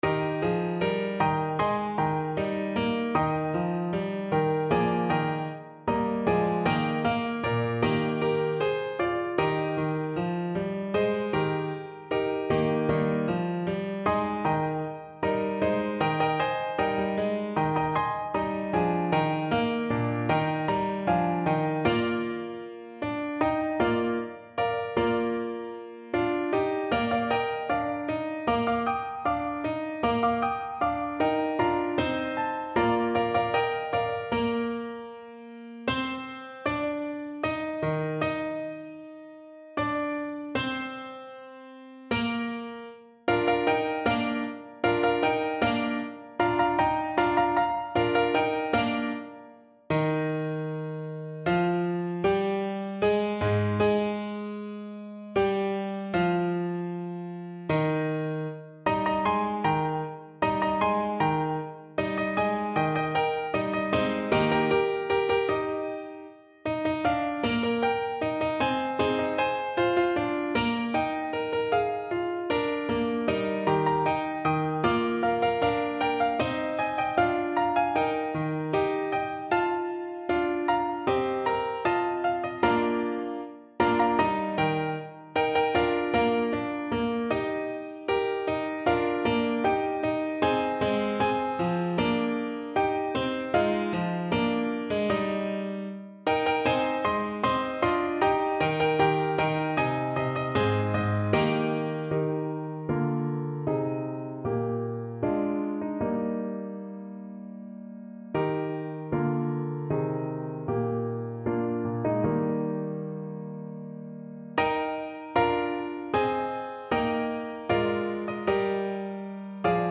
Free Sheet music for French Horn
French Horn
Eb major (Sounding Pitch) Bb major (French Horn in F) (View more Eb major Music for French Horn )
4/4 (View more 4/4 Music)
Allegro (View more music marked Allegro)
Eb4-Bb5
Classical (View more Classical French Horn Music)
Baroque Music for French Horn